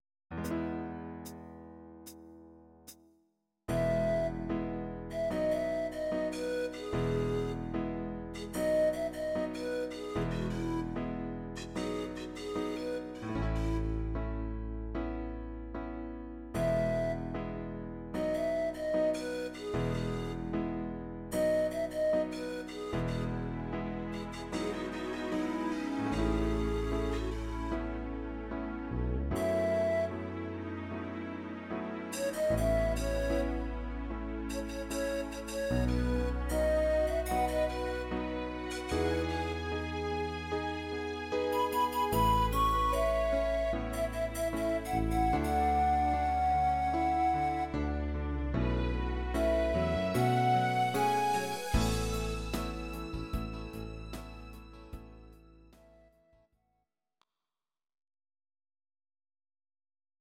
Audio Recordings based on Midi-files
Pop, Ital/French/Span, Duets, 2000s